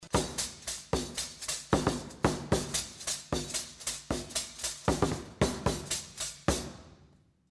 bębenek
bebenek.mp3